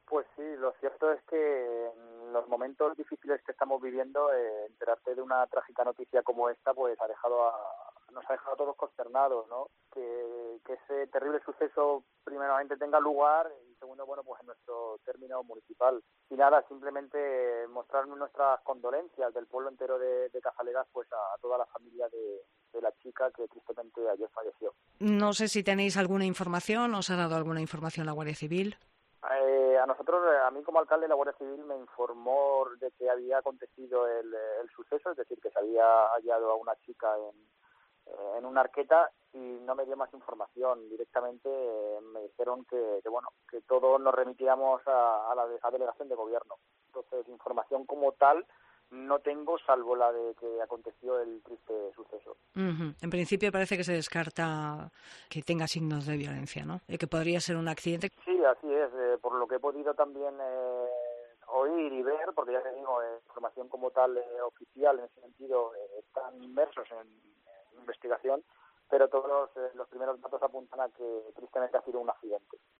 Declaraciones alcalde de Cazalegas